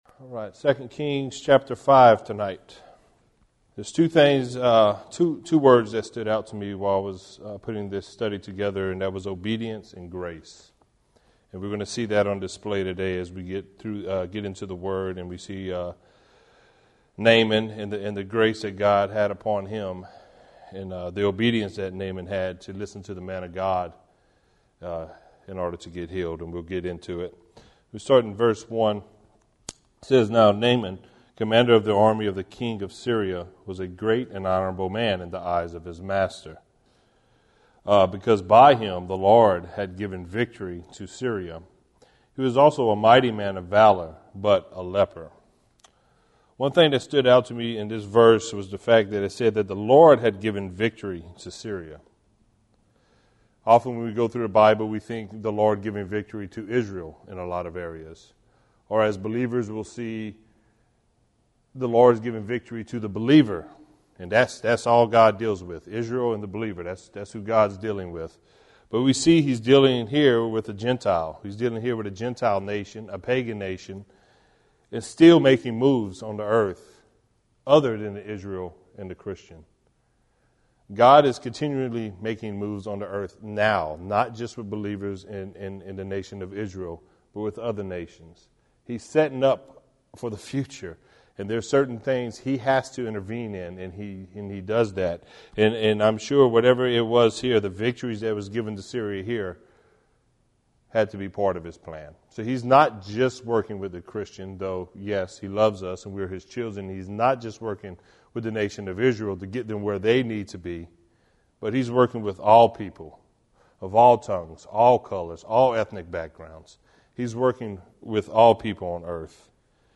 Join us for this verse by verse study in the book of 2 Kings